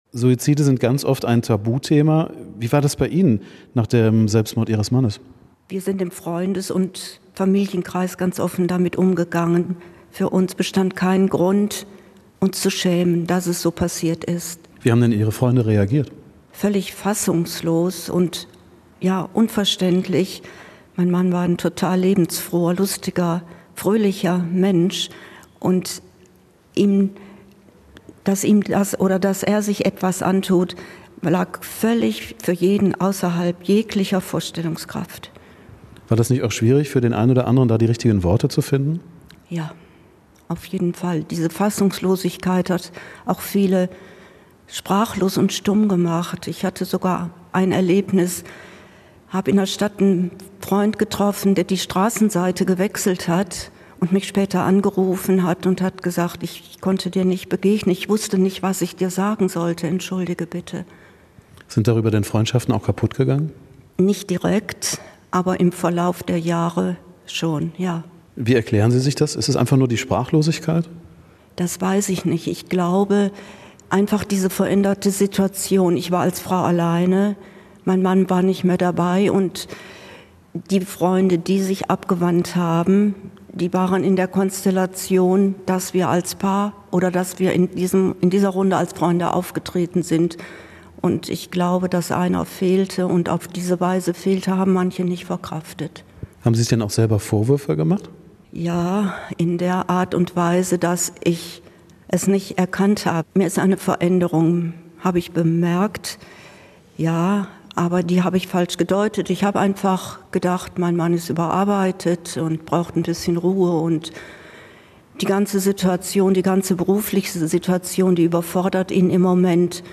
Tabu-Thema Suizid bei Radio Essen - Gespräch mit einer Betroffenen - Radio Essen
Vorwürfe, Zweifel, Trauer und auch Wut spielen eine Rolle. Zum Tag der Suizidprävention sprechen wir mit einer Betroffenen.